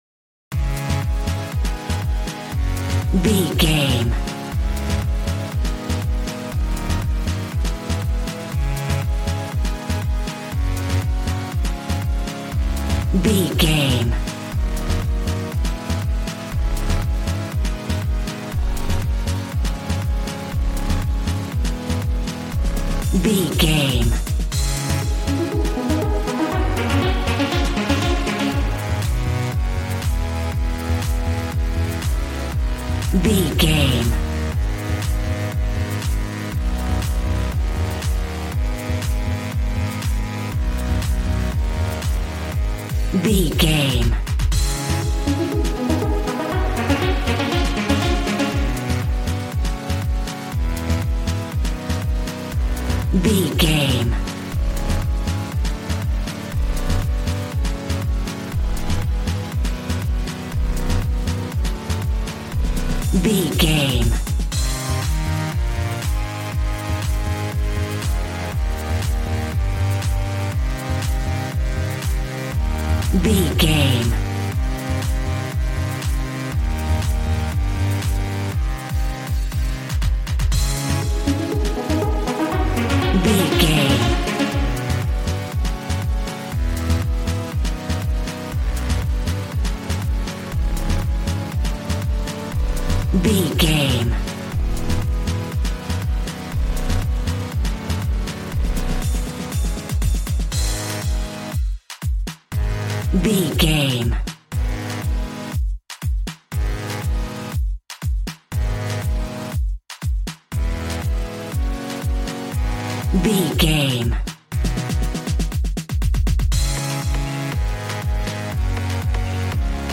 Aeolian/Minor
A♭
Fast
uplifting
lively
groovy
synthesiser
drums